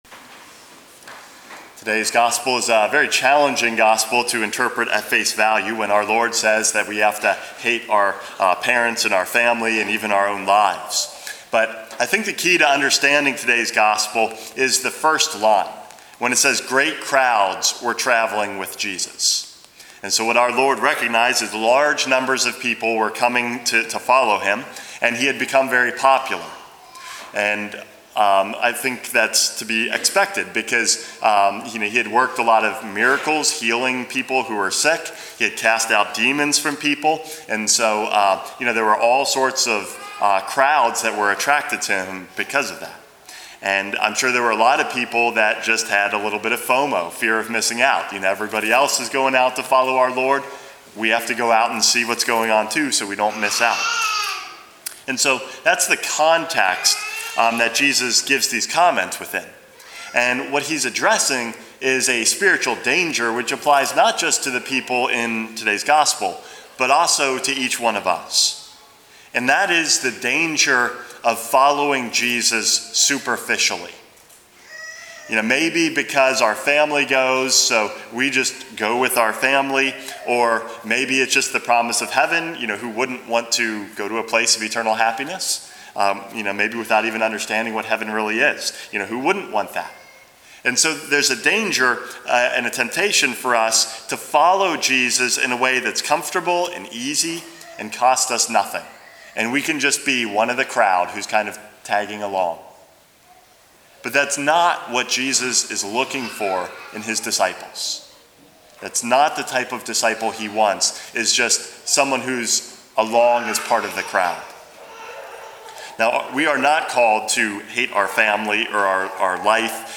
Homily #463 - True Disciples